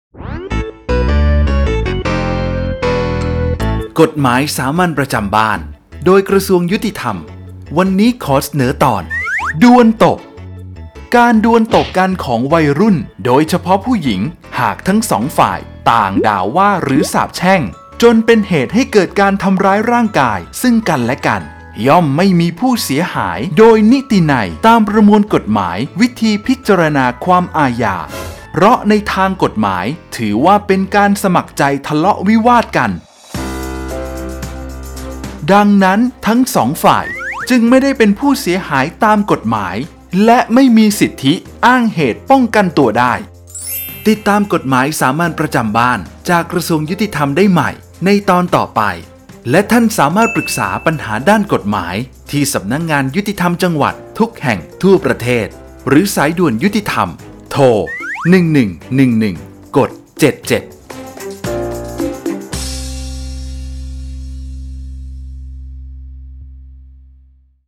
กฎหมายสามัญประจำบ้าน ฉบับภาษาท้องถิ่น ภาคกลาง ตอนดวลตบ
ลักษณะของสื่อ :   คลิปเสียง, บรรยาย